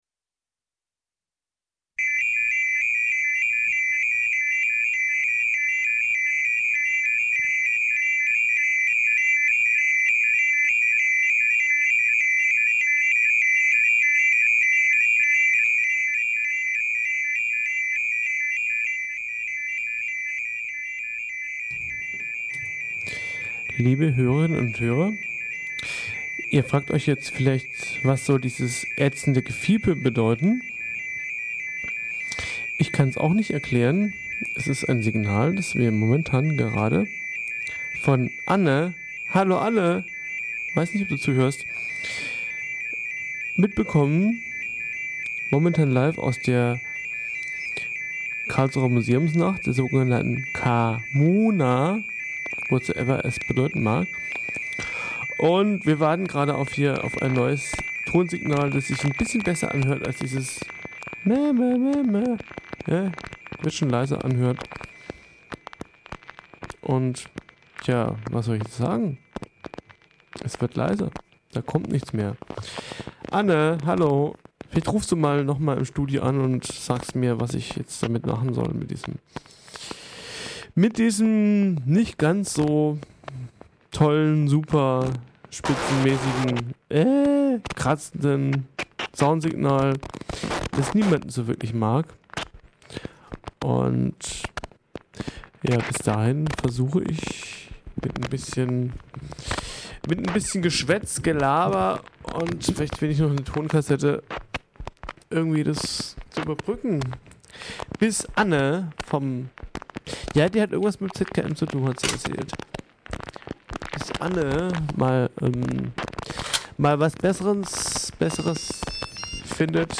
thinking Kofferadio Karlsruhe Ein vor Ort produziertes Hörspiel wird über einen mobilen Radiosender übertragen.
Die Sendung wurde vom Querfunk mitgeschnitten.
Equipment: mobiler Radiosender, tragbarer Schallplattenspieler, Minidisk Player, Mixer, Kofferradios